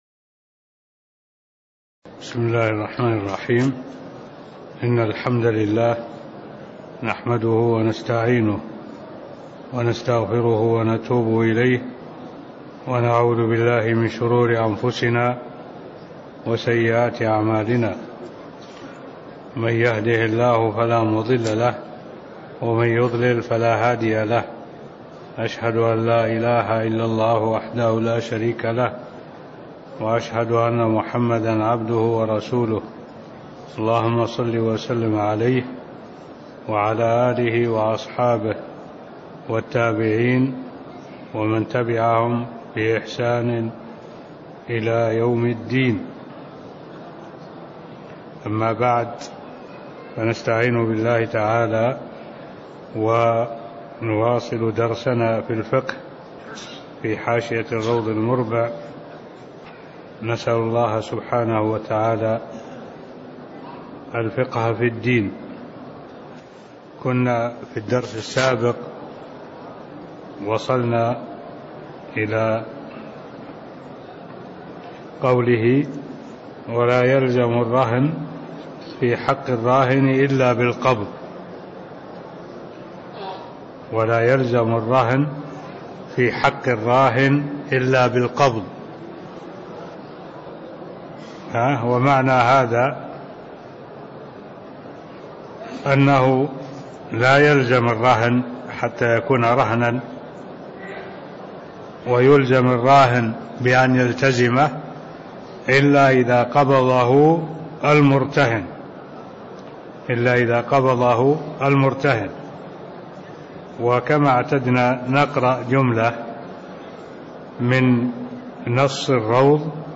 المكان: المسجد النبوي الشيخ: معالي الشيخ الدكتور صالح بن عبد الله العبود معالي الشيخ الدكتور صالح بن عبد الله العبود قوله: (ولايلزم الرهن في حق الراهن الا بالقبض) (04)